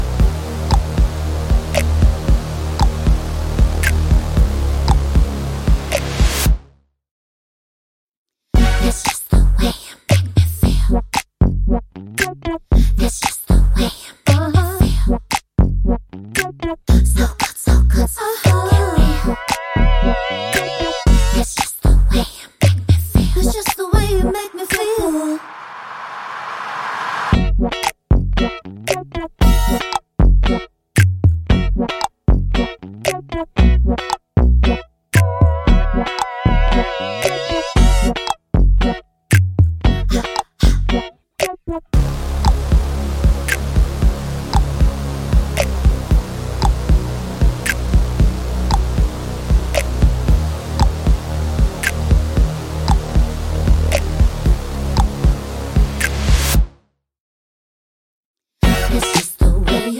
With Clean Backing Vocals Pop (2010s) 3:15 Buy £1.50